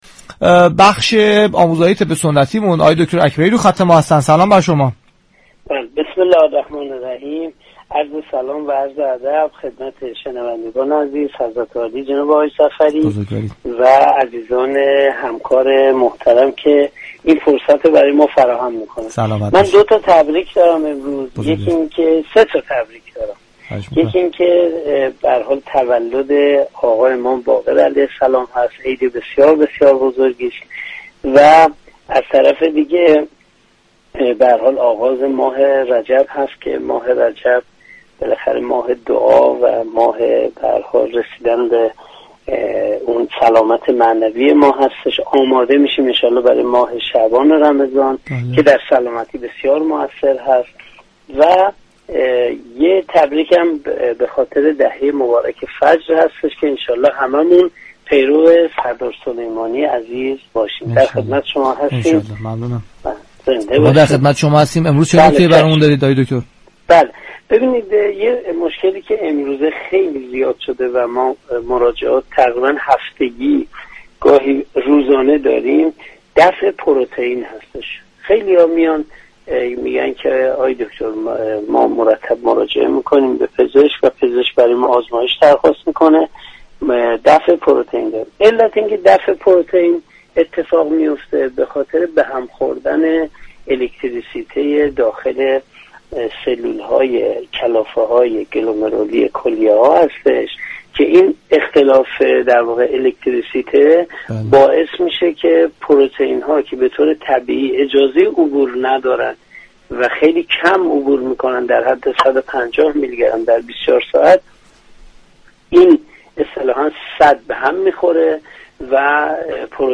در گفتگو با برنامه تسنیم رادیو قرآن